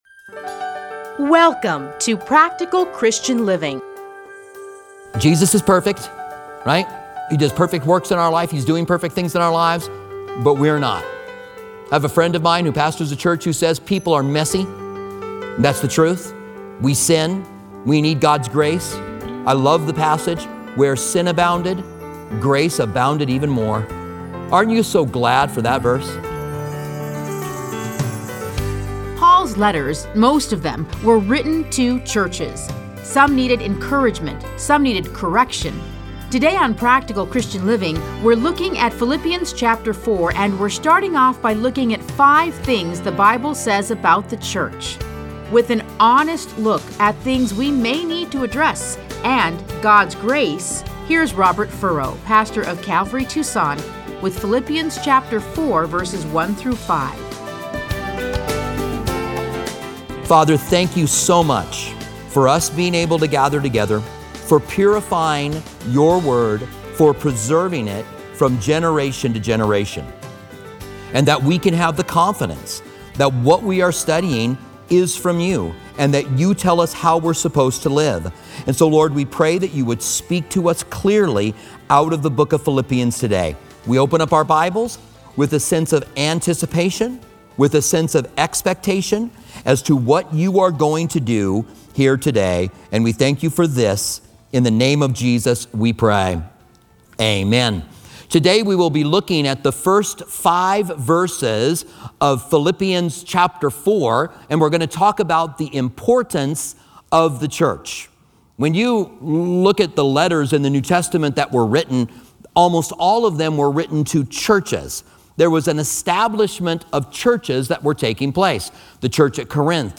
Listen to a teaching from A Study in Philippians 4:1-5.